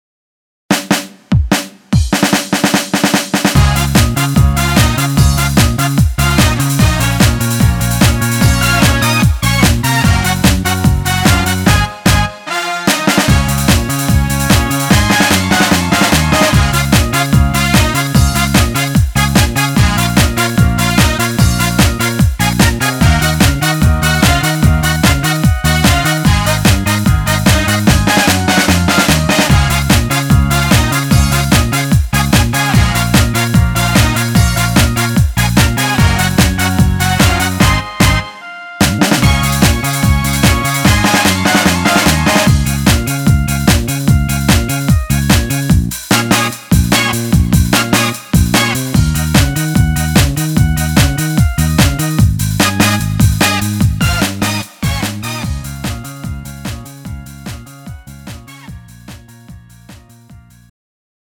음정 -1키 3:22
장르 가요 구분 Pro MR